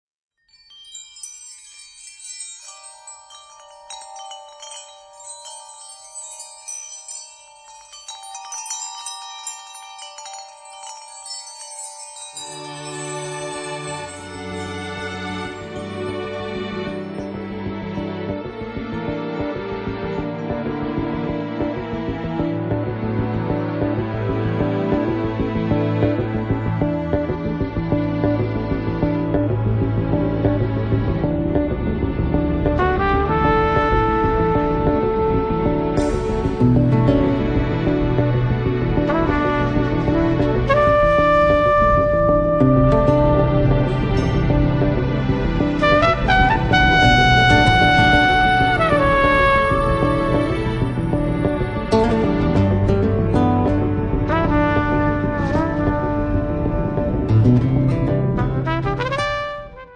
tromba, effetti
pianoforte, piano elettrico, synth